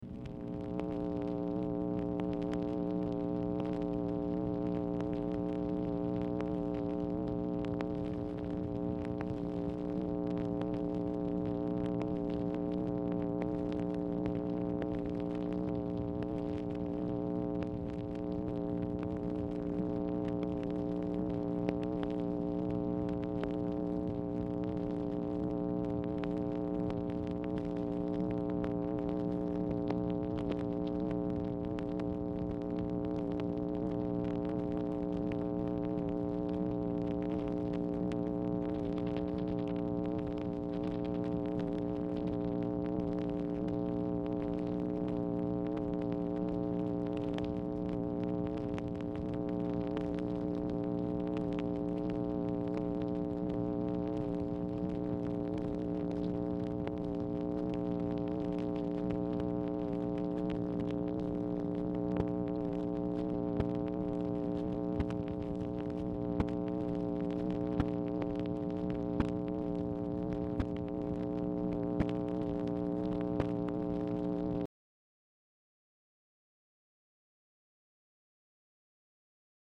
Format Dictation belt
Series White House Telephone Recordings and Transcripts Speaker 2 MACHINE NOISE Specific Item Type Telephone conversation